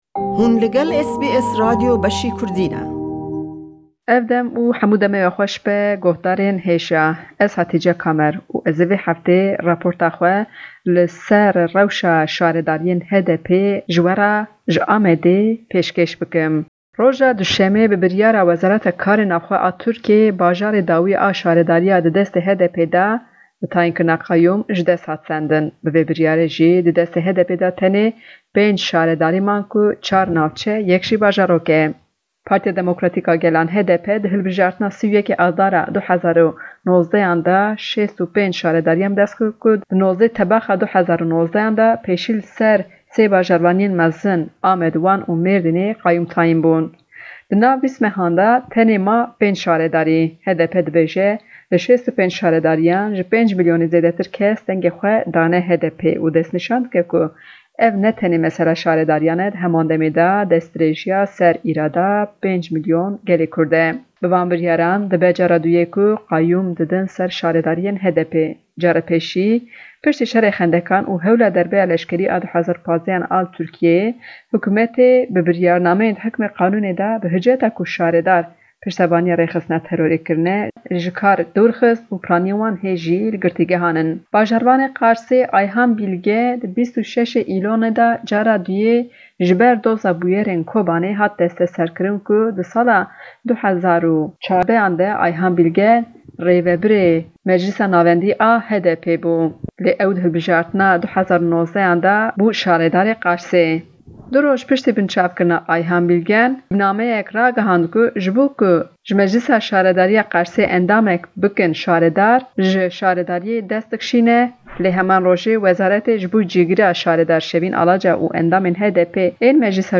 Raporta
Herî dawî bajarê Qers ji destê HDPê girtin û Hevşaredar Ayhan Bîlgen û Şevîn Alaca jî hatin girtin. Dengdêrên HDP û AKPê, sîyasetvanên HDP û AKPê herweha ji şîrovekarên sîyasî re poltîka qayûman şîrove kirin.